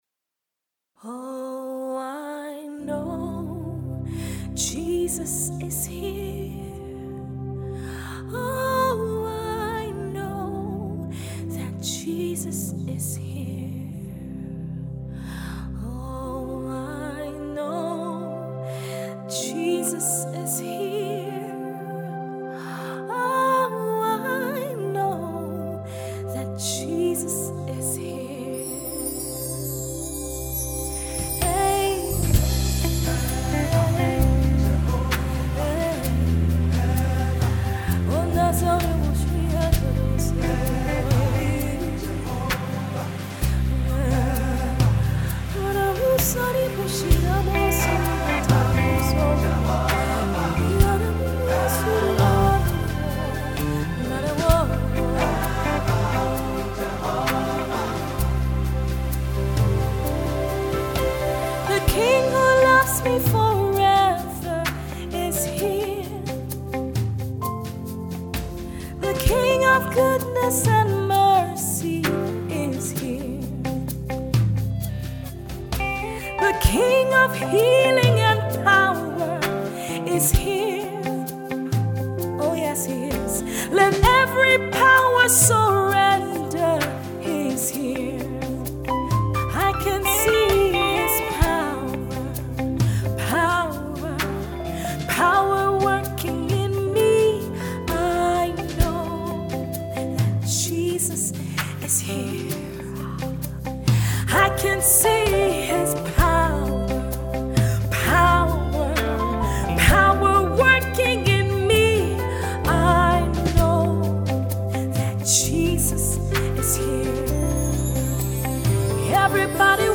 Gospel artiste